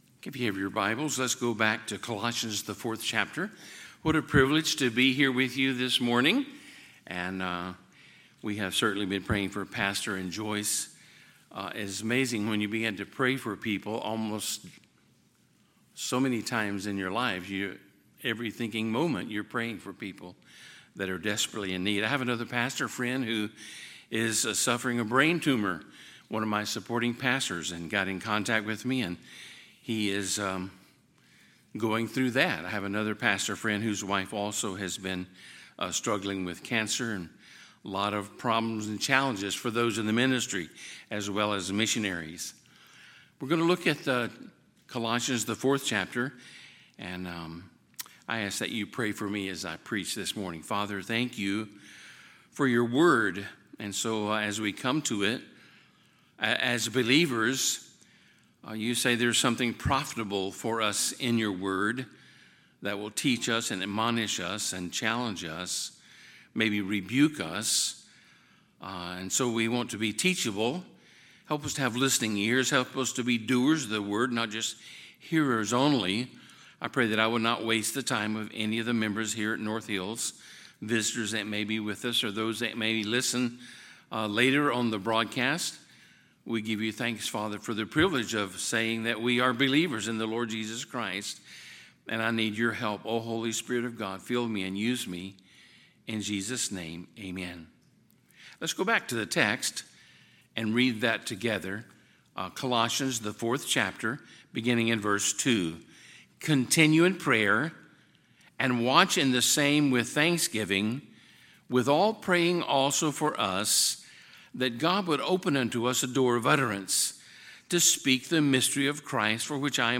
Sunday, August 13, 2023 – Sunday AM
Sermons